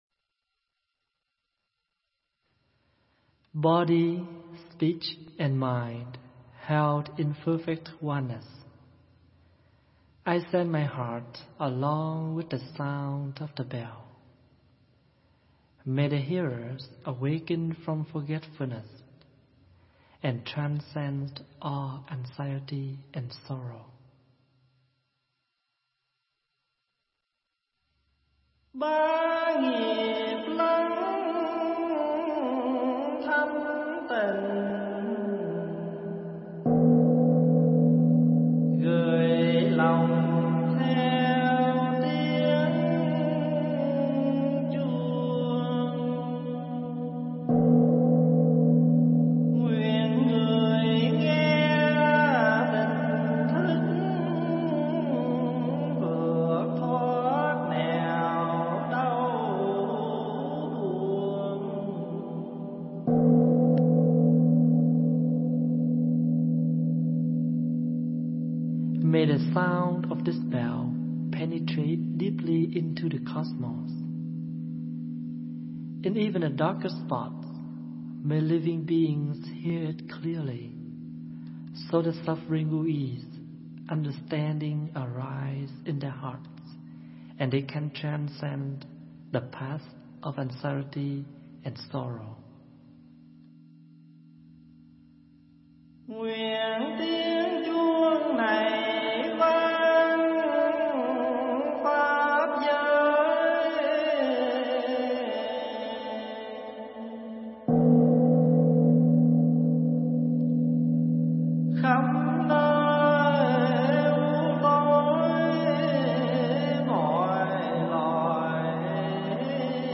thuyết giảng tại Tu Viện Trúc Lâm